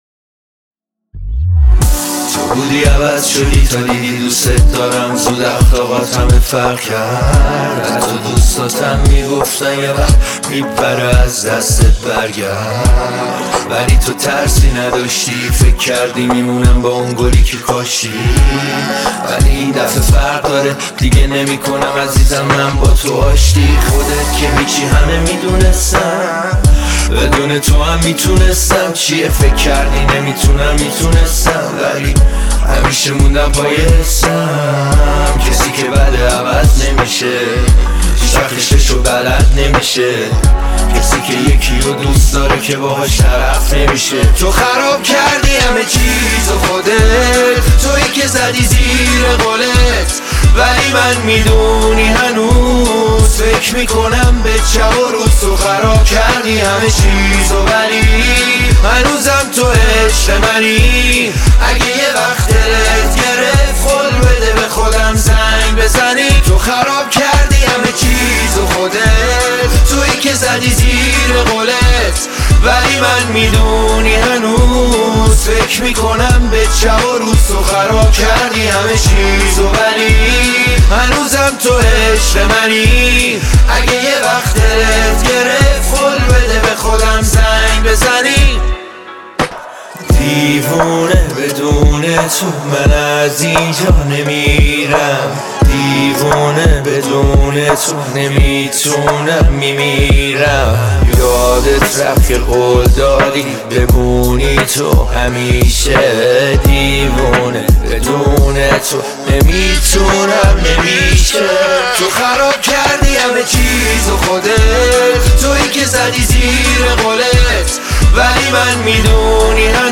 صداش آرامش خاصی رو از آدم میگیره